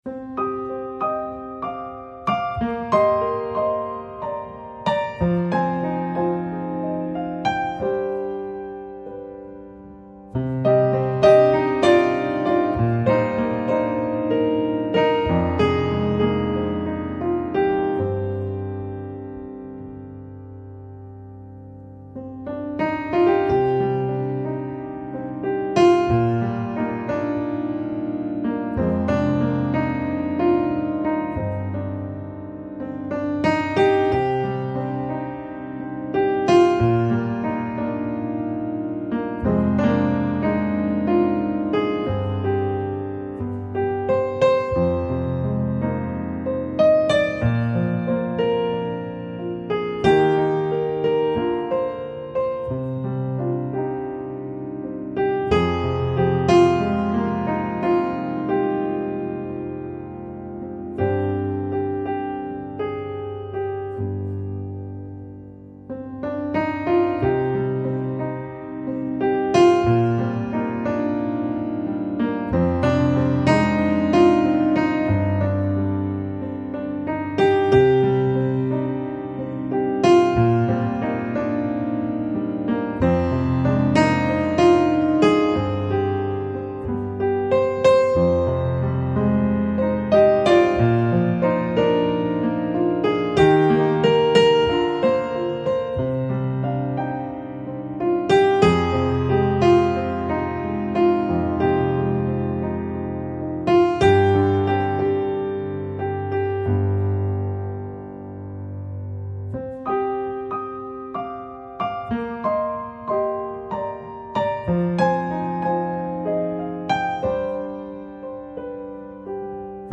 FLAC Жанр: New Age Год издания